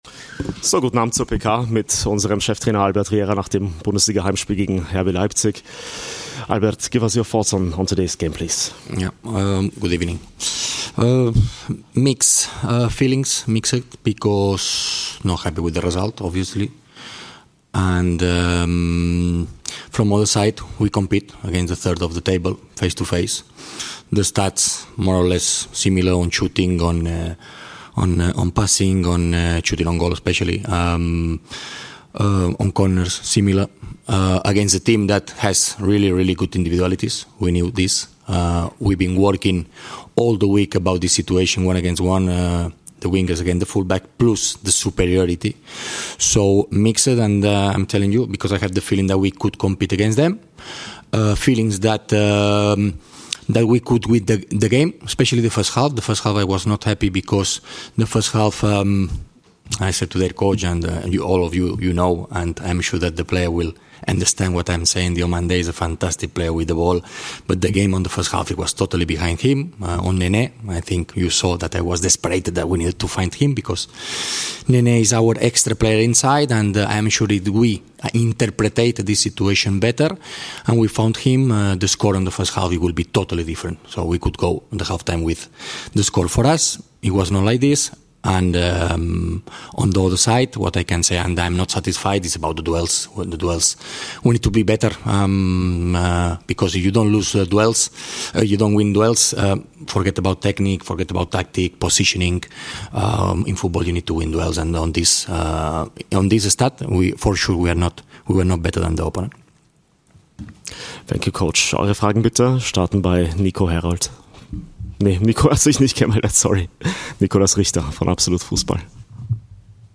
Die Pressekonferenz unseres Cheftrainer Albert Riera nach dem Spiel gegen Leipzig. Leipzig-Cheftrainer Ole Werner spricht im Anschluss.